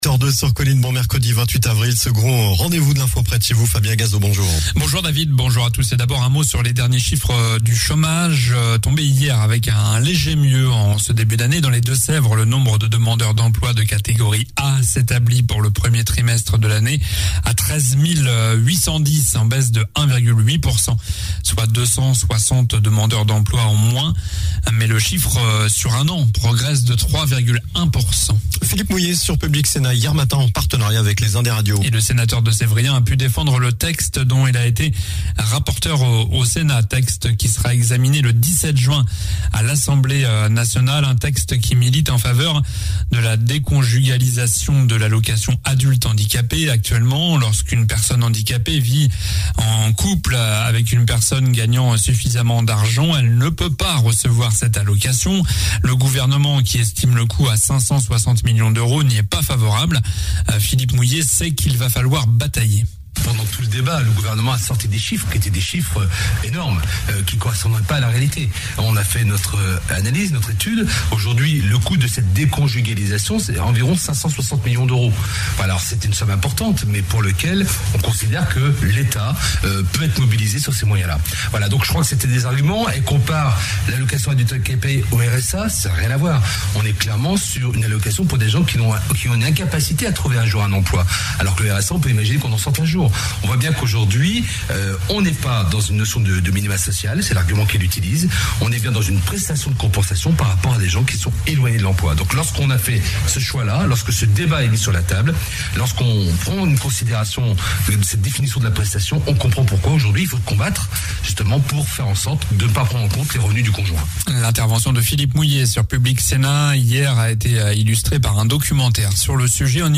JOURNAL DU MERCREDI 28 AVRIL (MATIN)